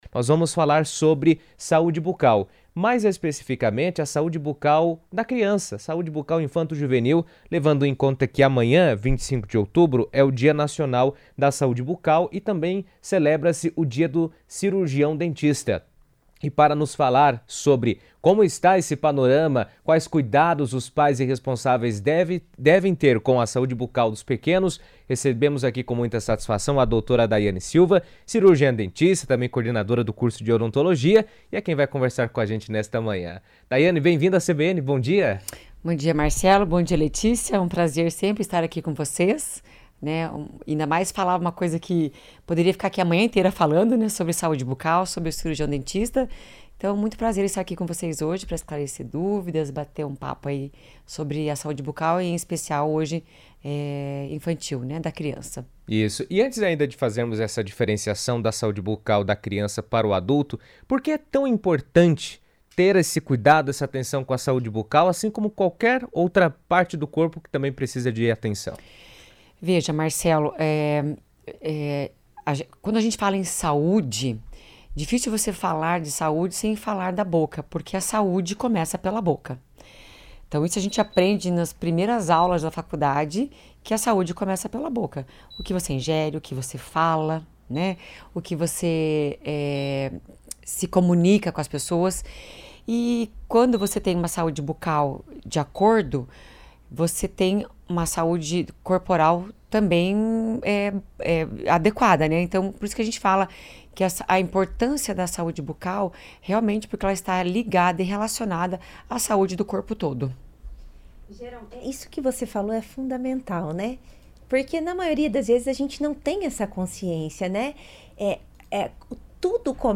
O Dia Nacional da Saúde Bucal, comemorado em 25 de outubro, reforça a importância de cuidados adequados com a higiene e a prevenção desde a infância. A atenção à saúde bucal infantil é essencial para evitar problemas futuros e promover o desenvolvimento saudável das crianças. Em entrevista à CBN